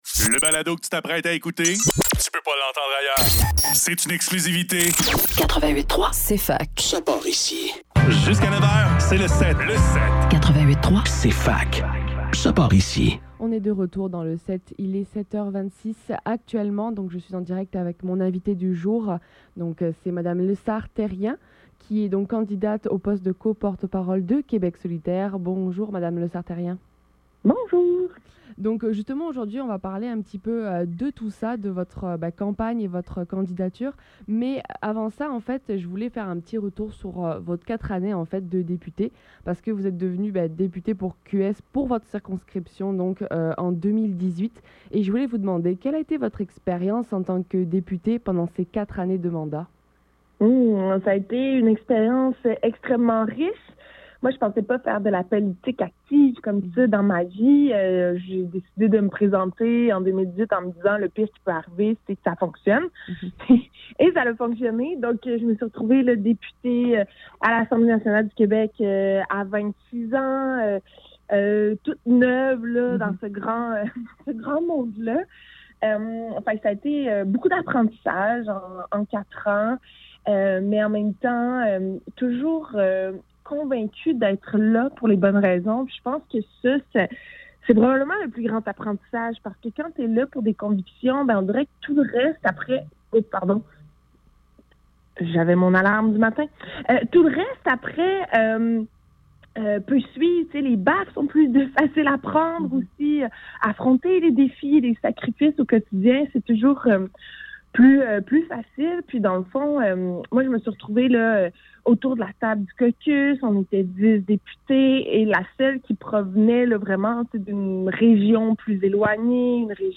Le SEPT - Entrevue Émilise Lessard-Therrien - 4 octobre 2023
Entrevue-milise-Lessard-Therrien.mp3